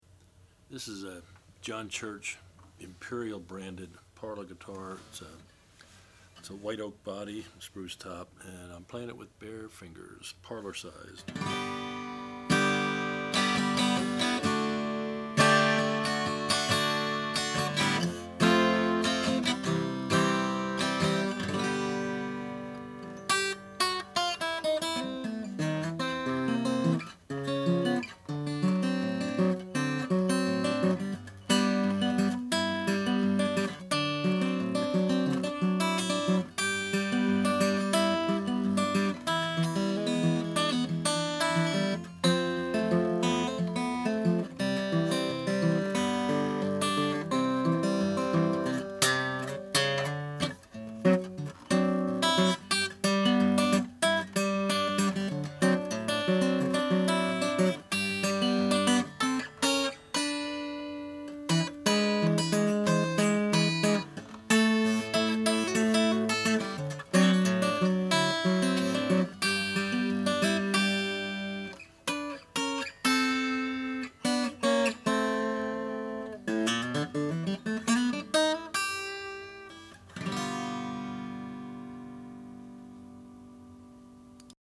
It features a white oak back and sides, unbound. The ladder-braced top is spruce with two inlaid purfling rings.
This is a true 'parlor' guitar in that is was actually made at the tail end of the parlor era, and features the modest dimensions typical of the era.
We've always been a fan of white oak, and this guitar is one of those where players will, after a few strums, exclaim, "Such a big sound from a small body!"